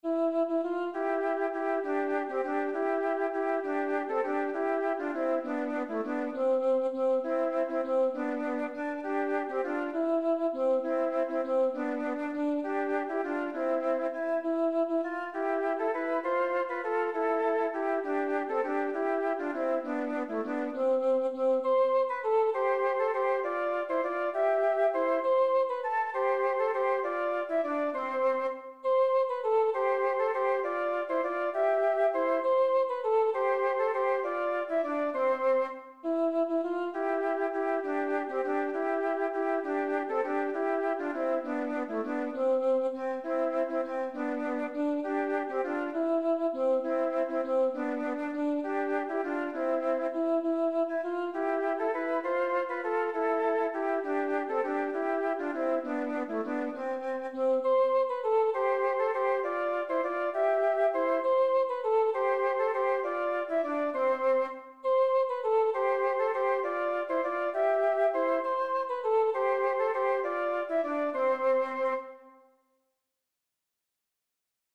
Para iso, é importante que identifiques os catro temas desta cantiga.
Cada 8 pulsos cambia o tema.